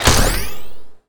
sci-fi_weapon_reload_10.wav